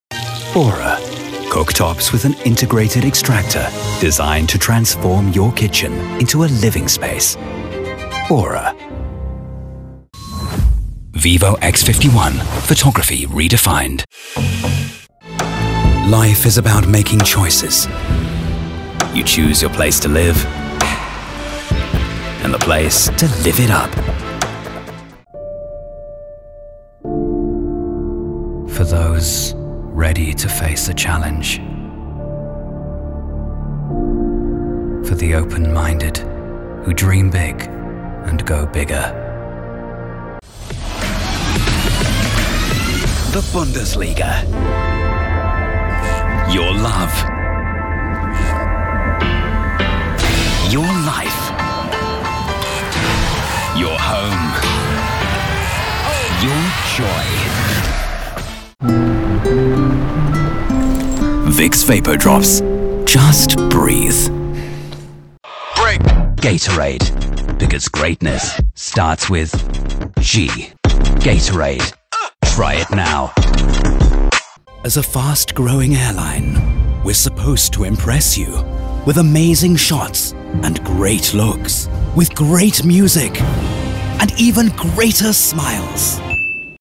Advertising reel